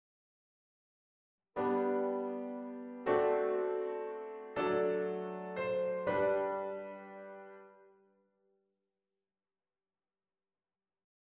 Uitgevoerd door Alexei Lubimov, op een historisch instrument.